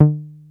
303 D#3 3.wav